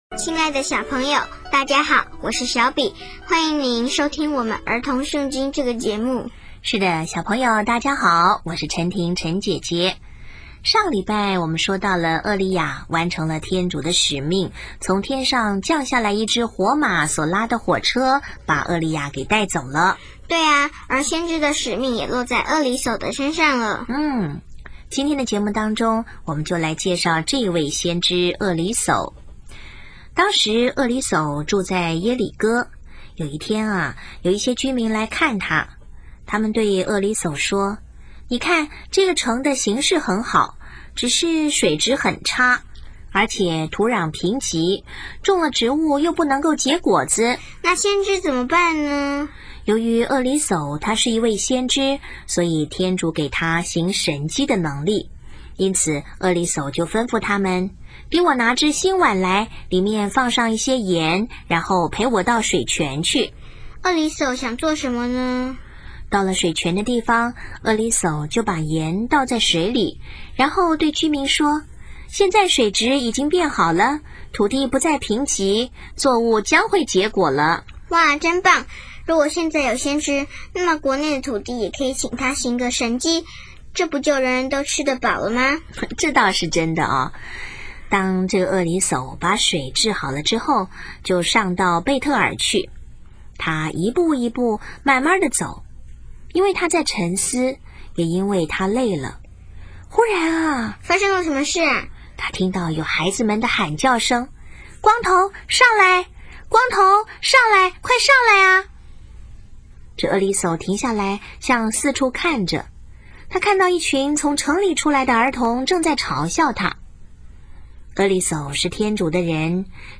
【儿童圣经故事】34|选民的分裂(三)厄里叟先知行奇迹